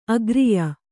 ♪ agriya